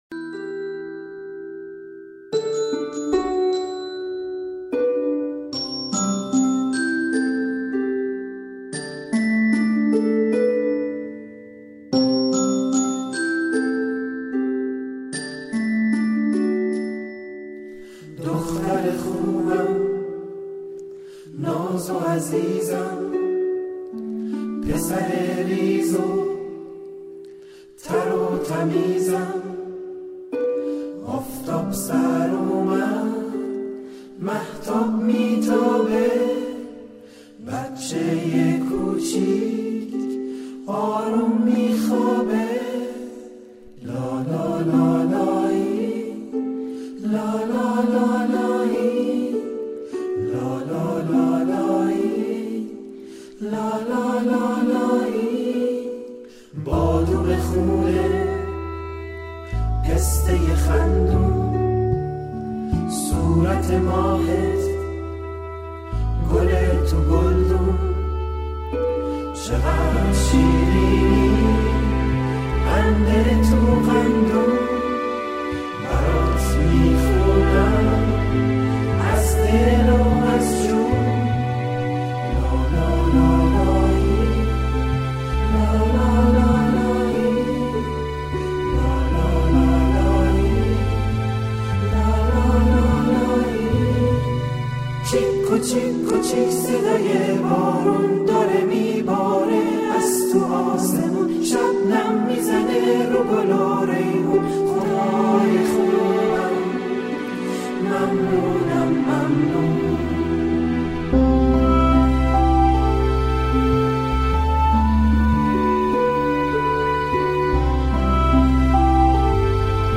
آهنگ لالایی